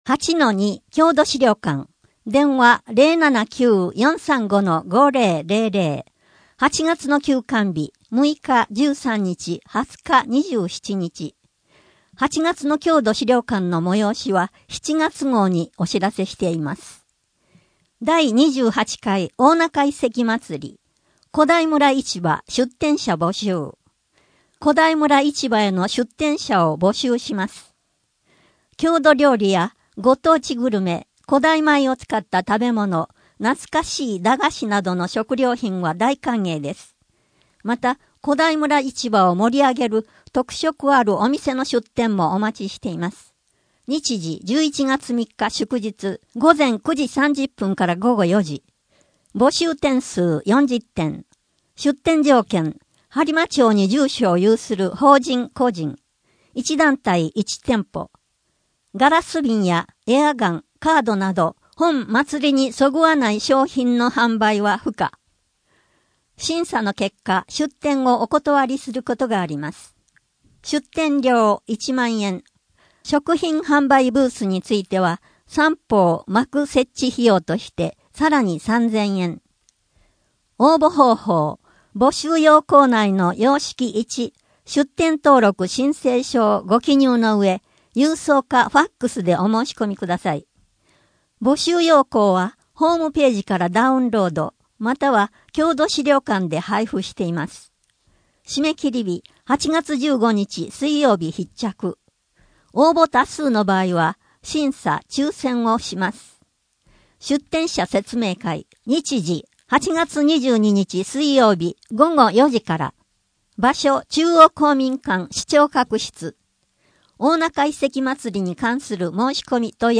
声の「広報はりま」8月号
声の「広報はりま」はボランティアグループ「のぎく」のご協力により作成されています。